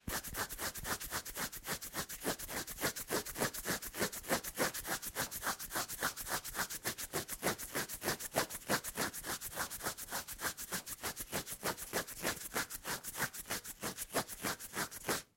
Звуки чесания
Звук почесывания чешуйчатой кожи пальцами